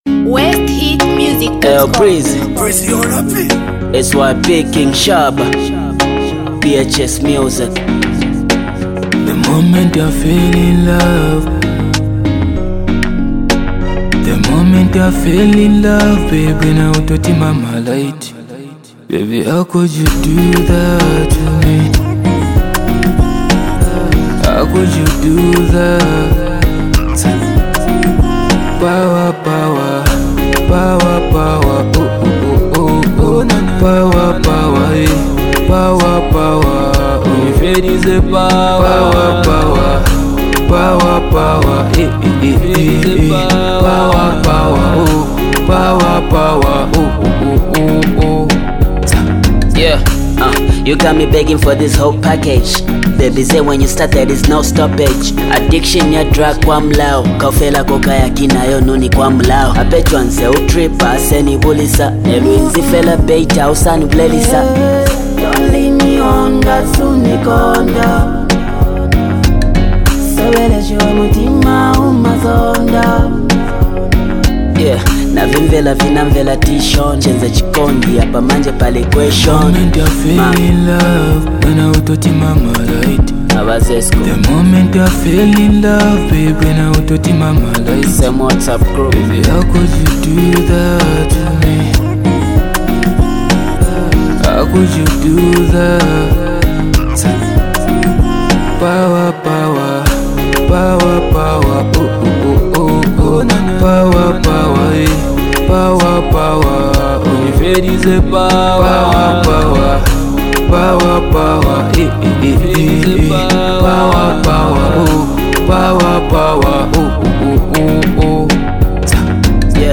New Sound Dance Song